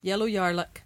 [yel-lo yAR-luck]